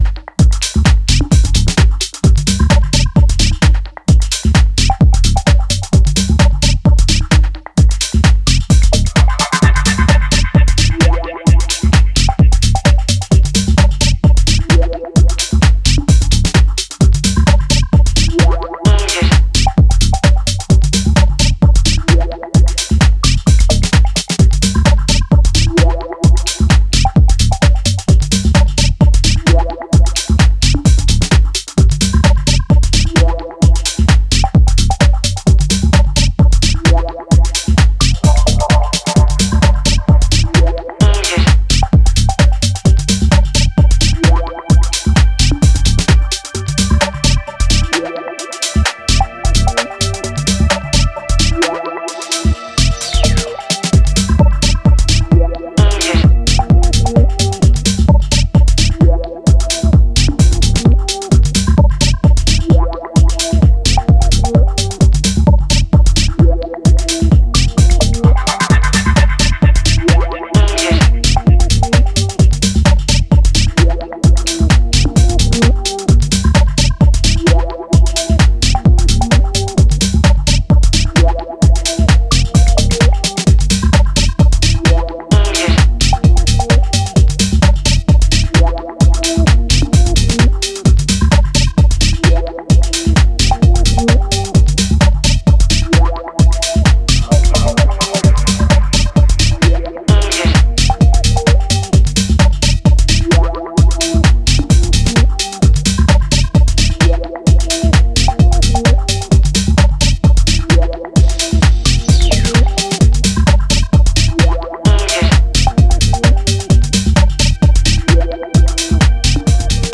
ここでは、流麗なシンセワークや推進力溢れるグルーヴを駆使したクールなミニマル・テック・ハウスを展開しています。